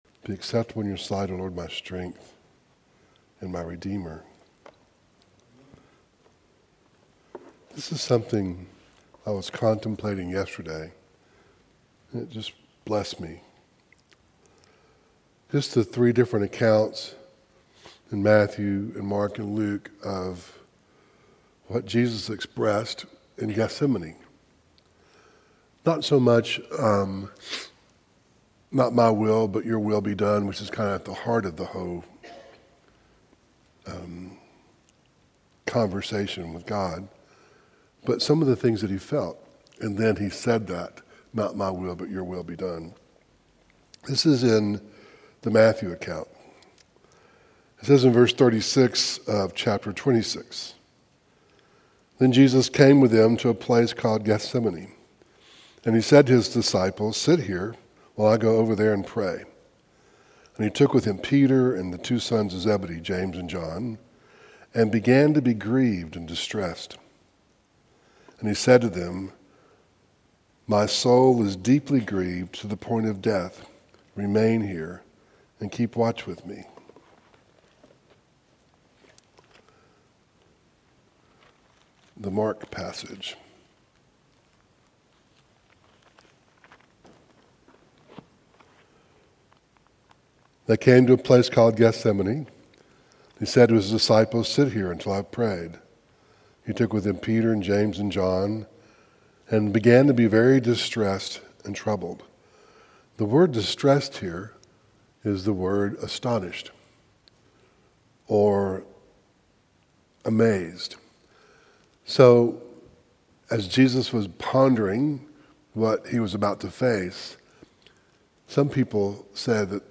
John 16:33 Service Type: Devotional Matthew 26:36-38